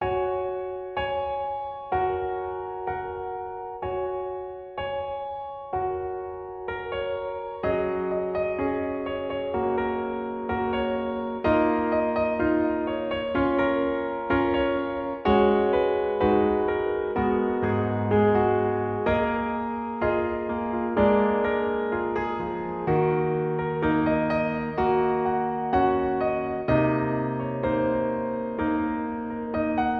• 🎹 Instrument: Piano Solo
• 🎼 Key: F# Major
• 🎶 Genre: Pop
emotional piano solo arrangement